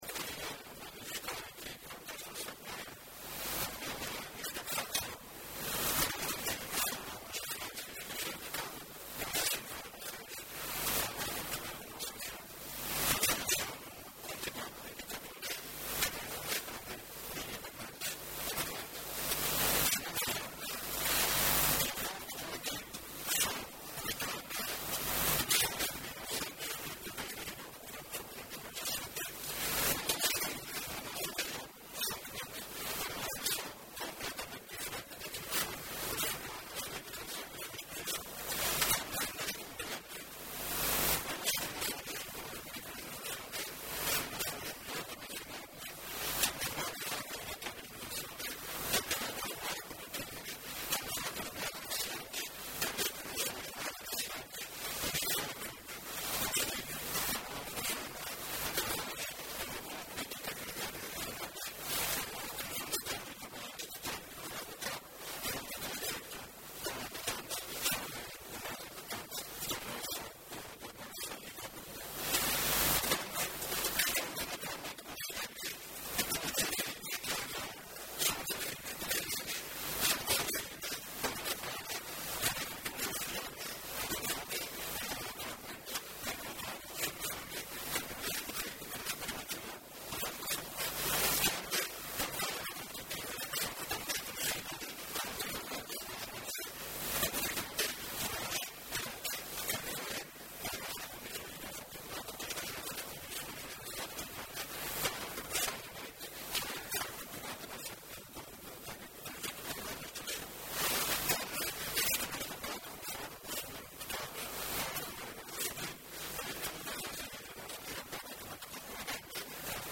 “Assistimos na nossa Região a uma evolução completamente diferente”, afirmou Luís Neto Viveiros, numa intervenção na Assembleia Legislativa, frisando que o CDS/PP referiu em plenário “uma quantidade de indicadores que não correspondem, minimamente, à verdade”.